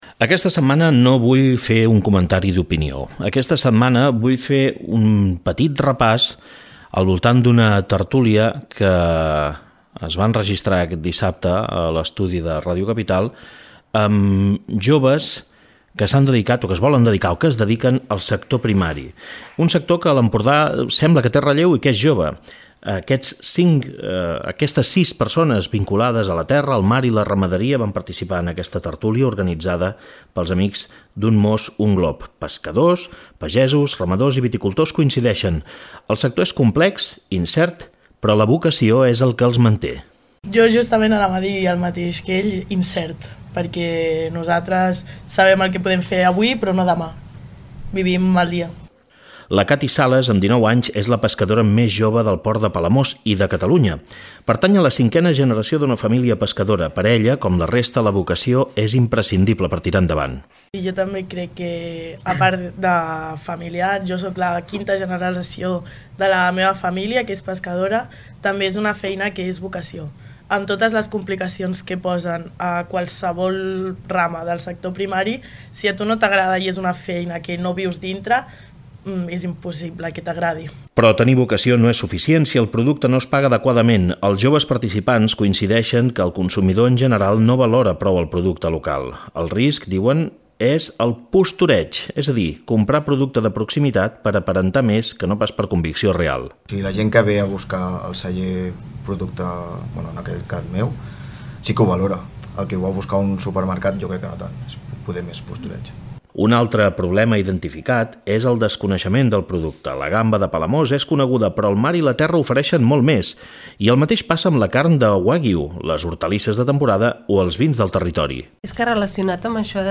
Supermatí - opinió